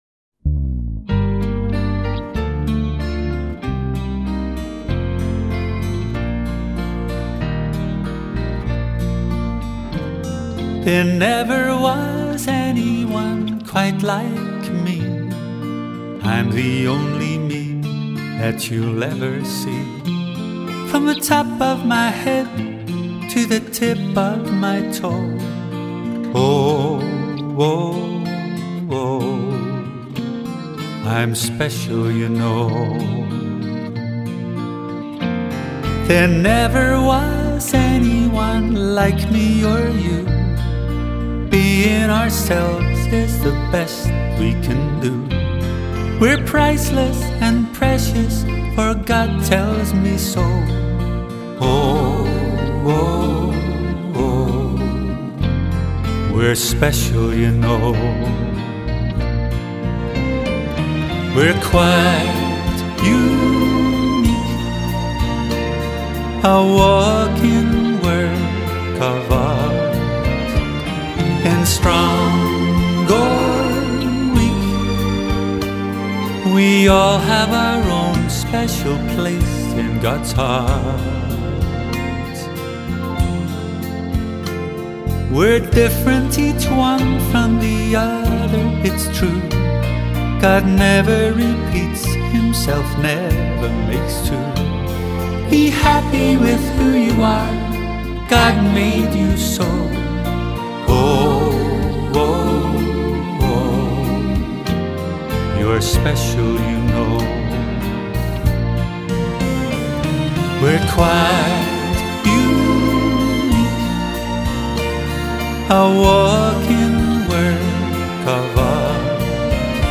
以一把清新的醇厚的嗓子献唱出十七首创作作品